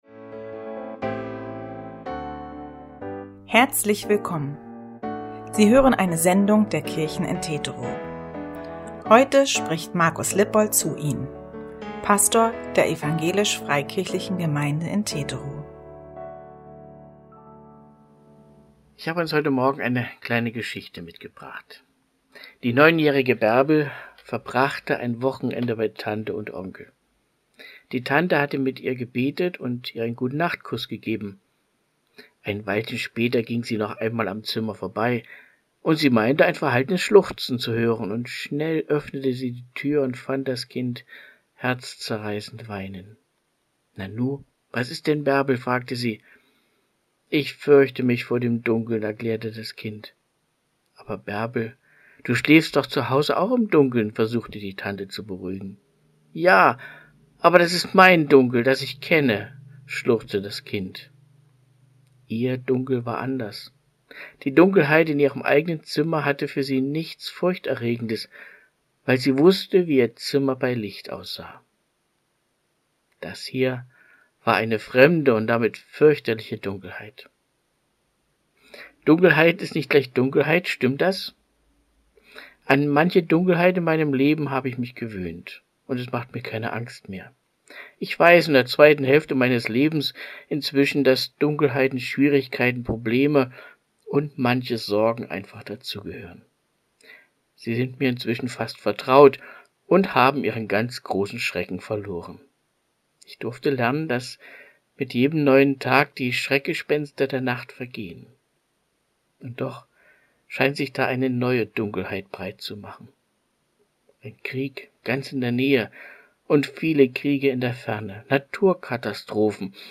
Audio-Andacht vom 17.07.2022